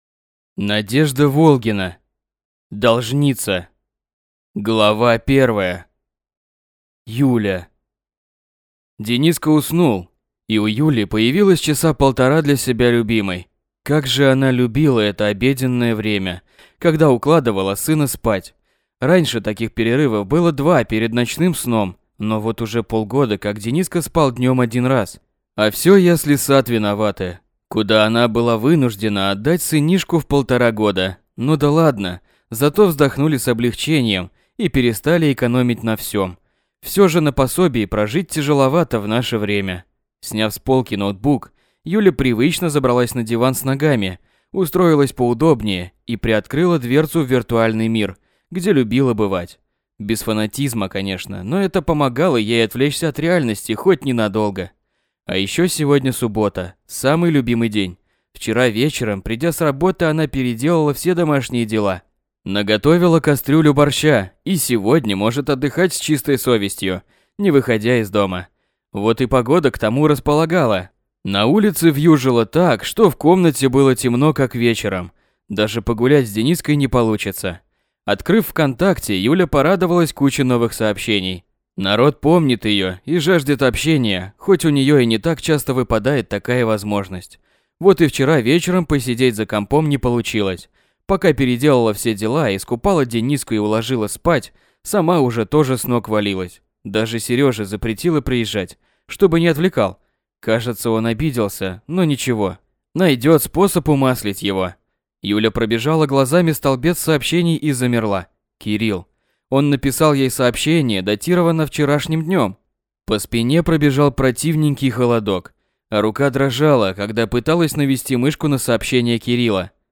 Aудиокнига Должница